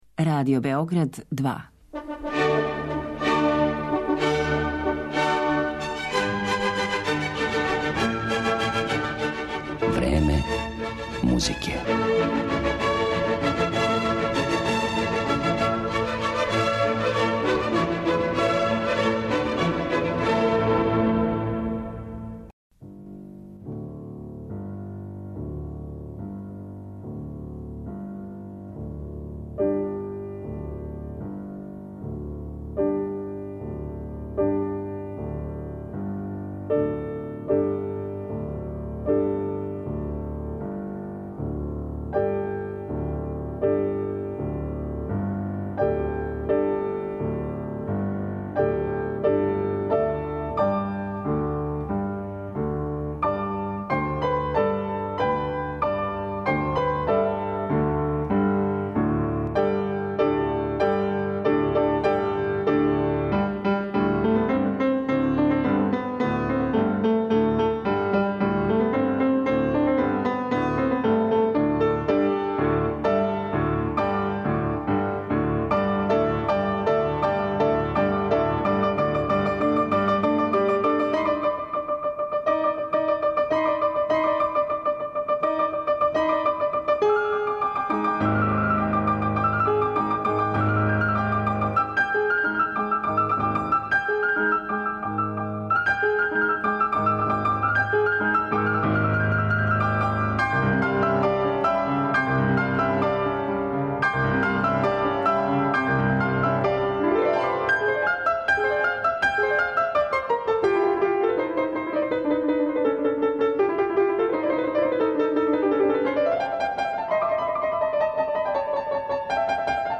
Чућете како је текло усвајање и уметничко уобличавање ове игре у разним жанровима и стиловима српске музике, од клавирских композиција и хорова, па до опере, балета, симфоније и концерта.